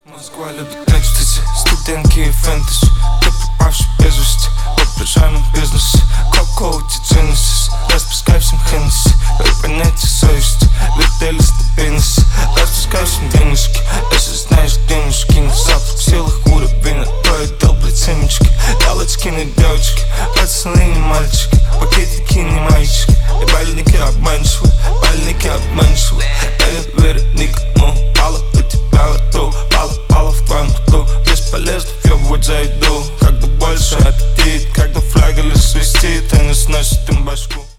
Рэп и Хип Хоп
тихие